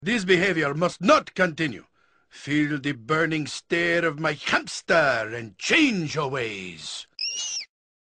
1 This is from the Baldur's Gate II: Shadows of Amn CD; I used a microphone by my computer speaker to record it.